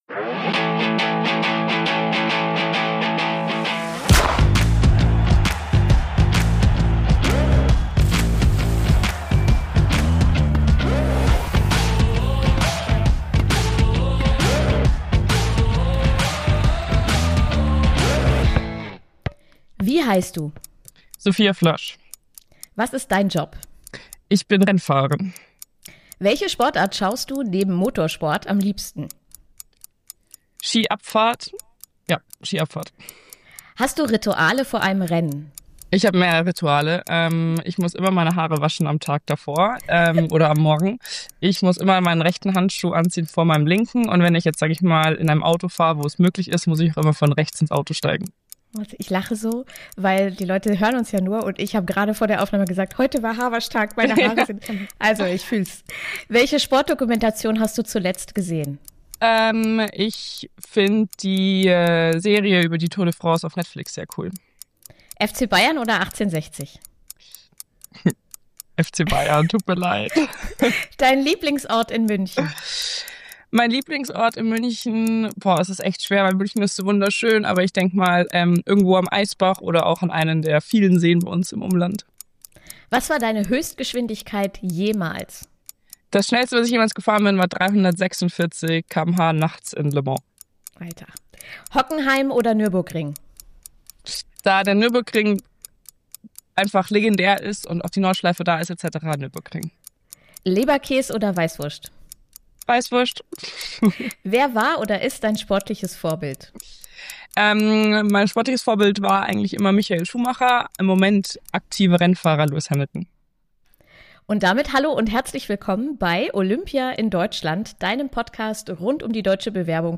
Im Gespräch mit Moderatorin